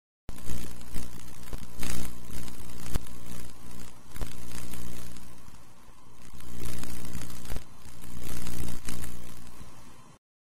Звуки неона
Погрузитесь в атмосферу ночного города с подборкой звуков неона: мерцание вывесок, тихий гул ламп, электронные переливы.